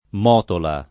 [ m 0 tola ]